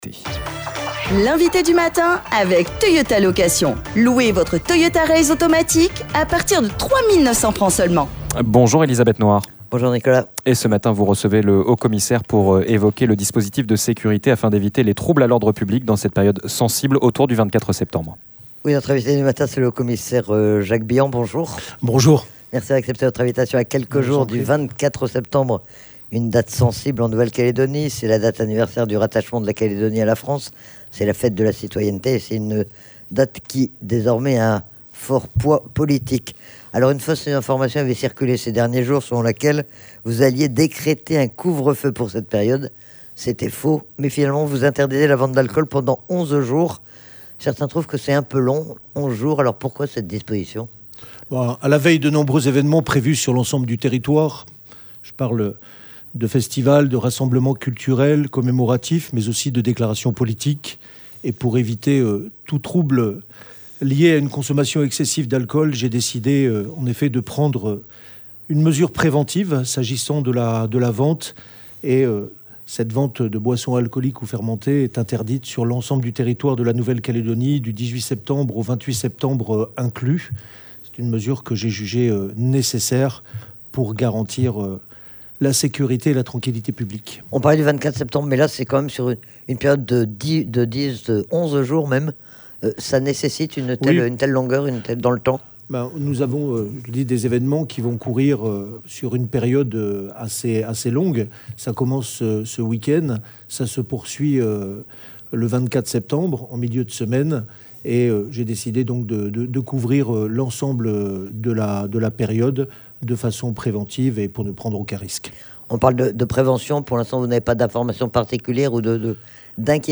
L'INVITÉ DU MATIN : LE HAUT-COMMISSAIRE JACQUES BILLANT
Quel dispositif de sécurité pour éviter les troubles à l'ordre public dans cette période sensible, à proximité du 24 septembre ? C'est le Haut-Commissaire Jacques Billant qui était notre invité du matin. Il était, notamment, interrogé sur les effectifs des forces de l'ordre, sur l'état de la délinquance et sur les points de vigilance particuliers.